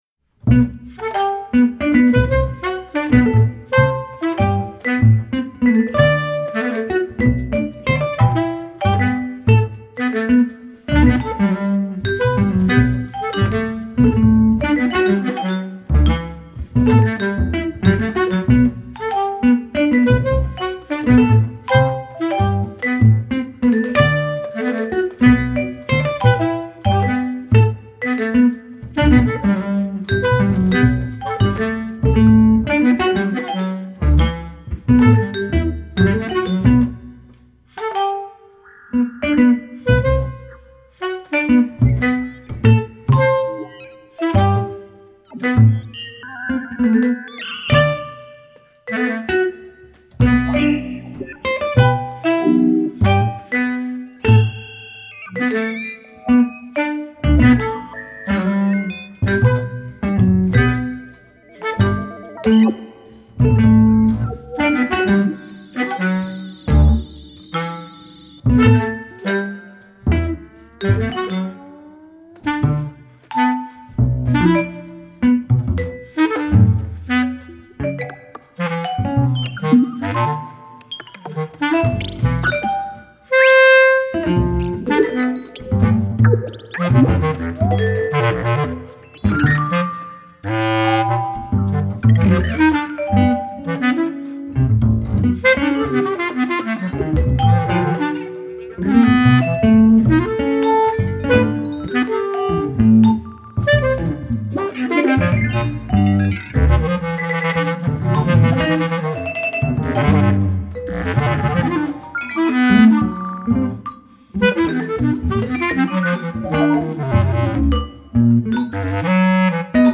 clarinet
guitar
vibraphone
electronics
bass